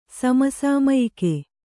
♪ sama sāmayike